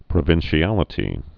(prə-vĭnshē-ălĭ-tē)